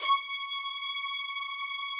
strings_073.wav